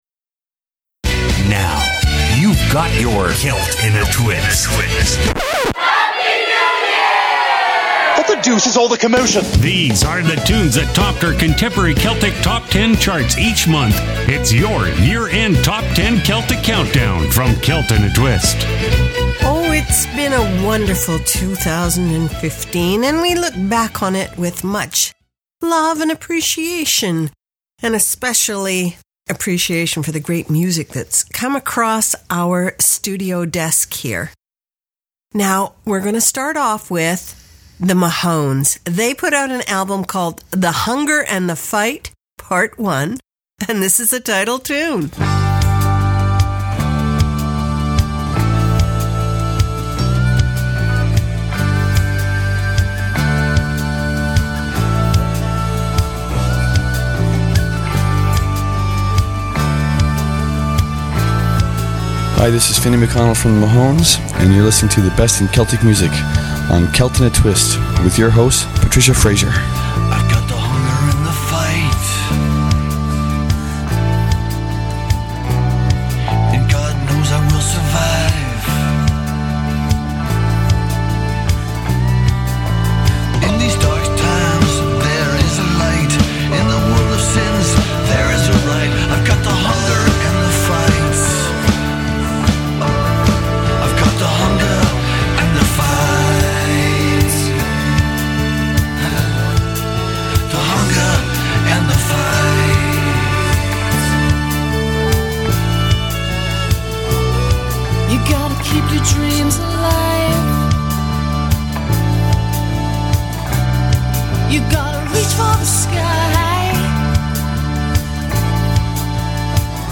OUR YEAR END BEST OF 2015 CONTEMPORARY CELTIC SPECIAL!
The best contemporary Celtic from 2015